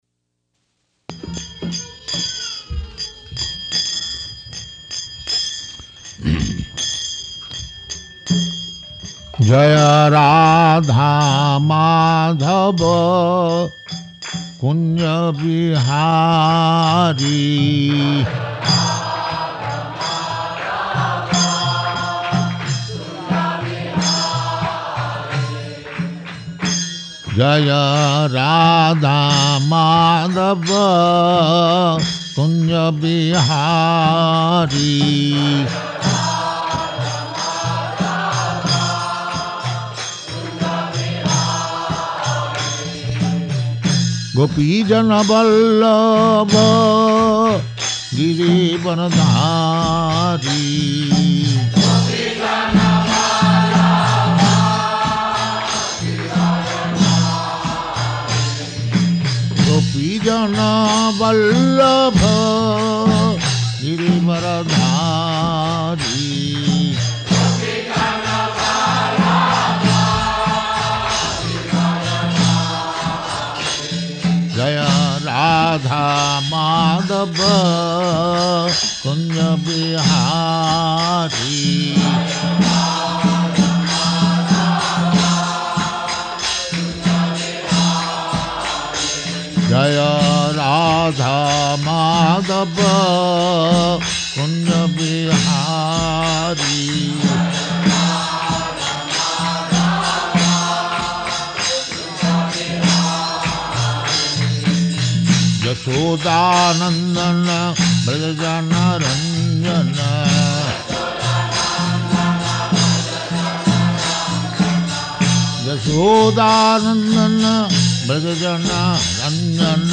Type: Lectures and Addresses
Location: Paris
[devotee translates into French throughout] Prabhupāda: [leads chanting of Jaya Rādhā-Mādhava ]
[devotees offer obeisances] [05:16] So I am very much pleased to come to this village of France.